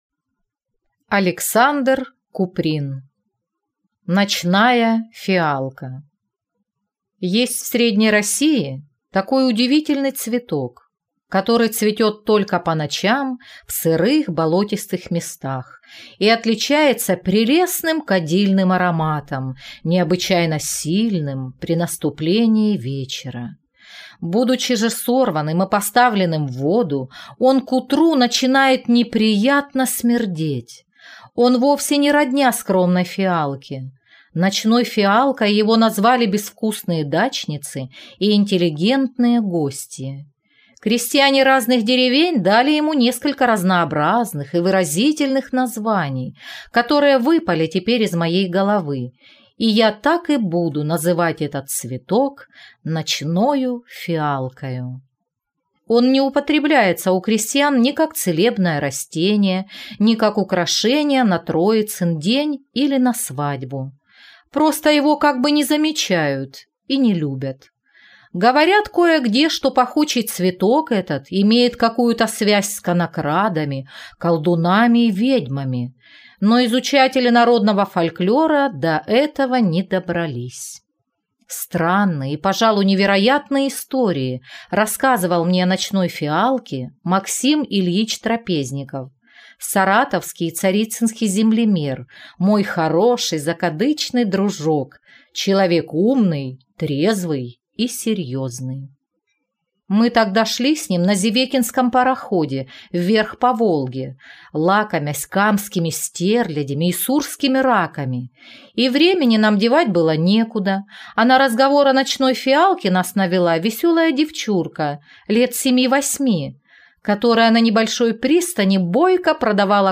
Аудиокнига Ночная фиалка | Библиотека аудиокниг